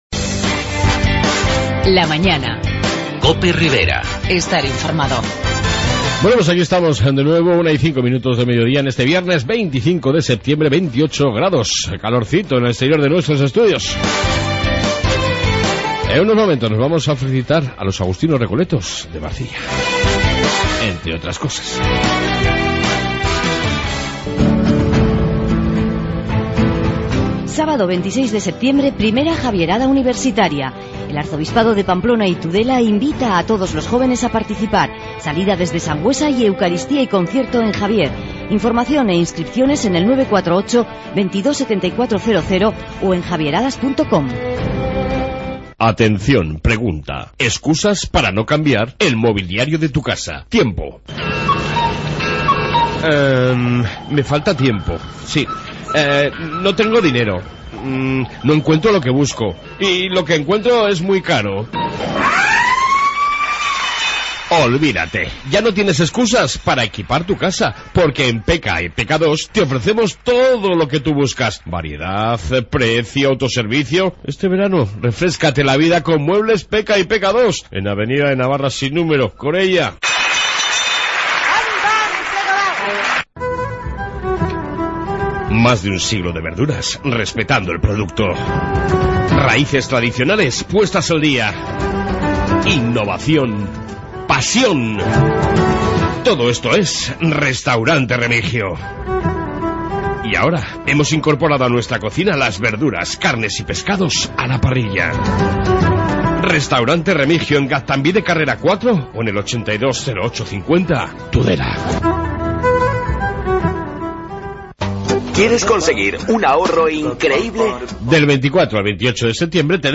AUDIO: En esta 2 parte, mas Noticias , entrevista con los Agustinos recoletos de Marcilla en su 150 aniversario y tiempo para el motor con Peugeot